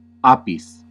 Ääntäminen
Vaihtoehtoiset kirjoitusmuodot apes Ääntäminen Classical: IPA: /ˈa.pis/ Haettu sana löytyi näillä lähdekielillä: latina Käännös Ääninäyte Substantiivit 1. bee US Suku: f .